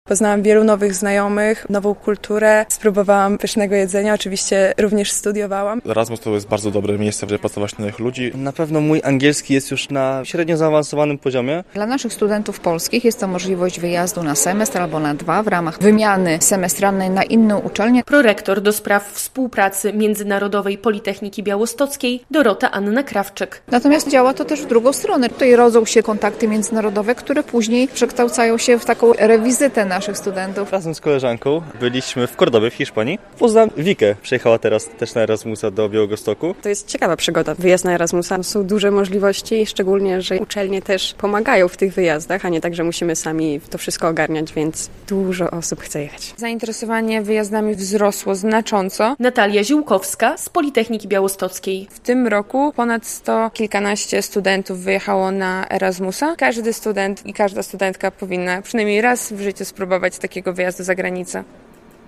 - Poznałam wielu nowych znajomych, nową kulturę, spróbowałam pysznego jedzenia, oczywiście, również studiowałam - mówi jedna ze studentek Politechniki Białostockiej.